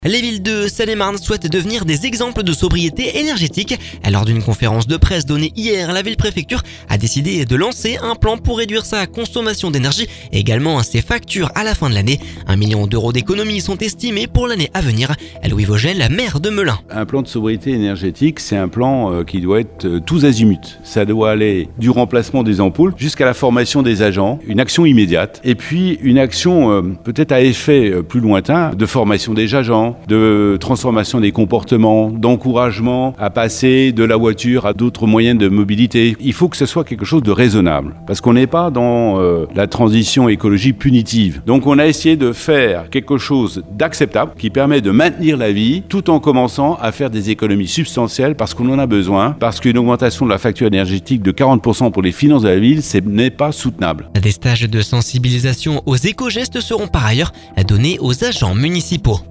Louis Vogel, maire de Melun…